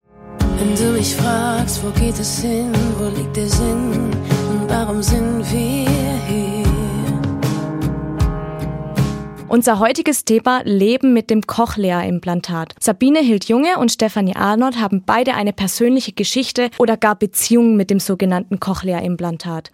Vom hochgradigen Hörverlust zum normalen Hören. Dies ist möglich mit dem sogenannten Cochlea Implantat (CI). Was das genau ist, wie es funktioniert und wie das Leben damit ist, erfahrt ihr in unserem kommenden Studiotalk.